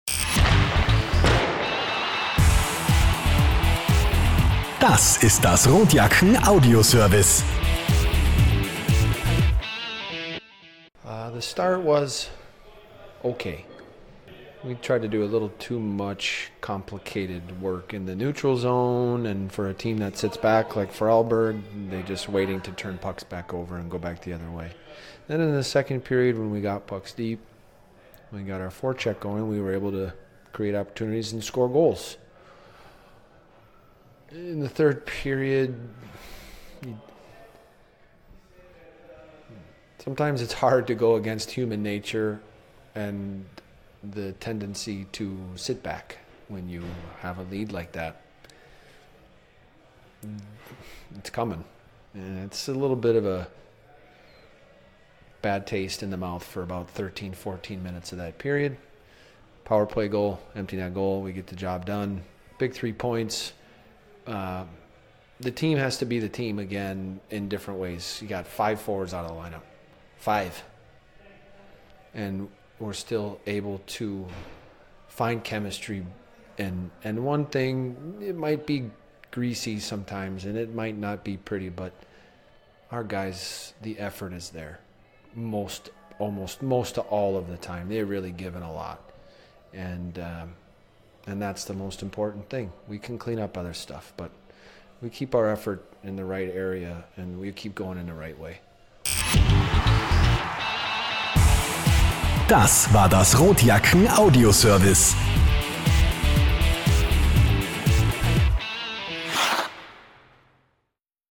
Post Game